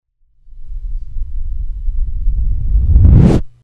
Swoosh SFX -
Swoosh-Sound-effect-5.mp3